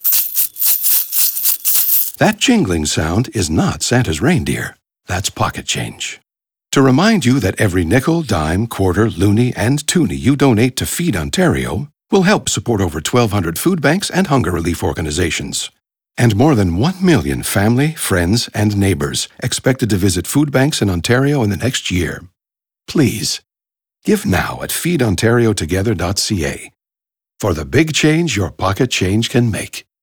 Pro Bono Group’s new Feed Ontario PSA is titled “Jingle Change.”  It can replace “Dinner For A Week” and continue to air until the end of December.